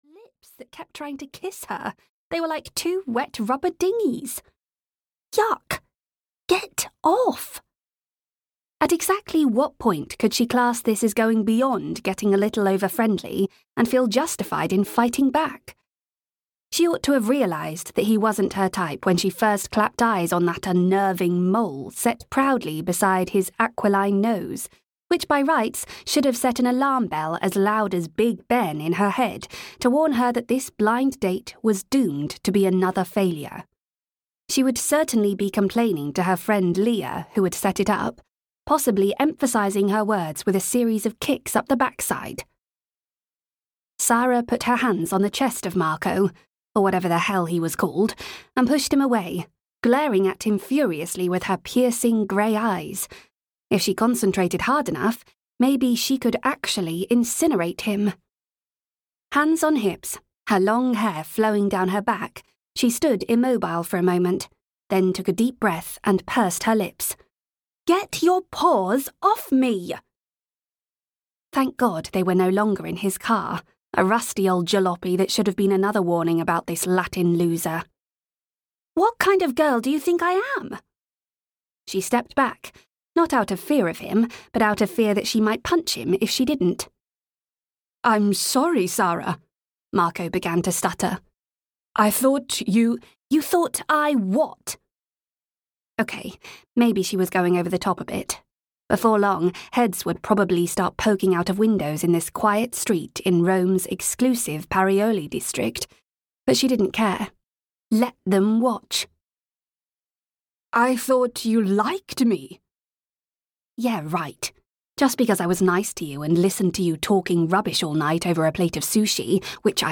Stuck on You (EN) audiokniha
Ukázka z knihy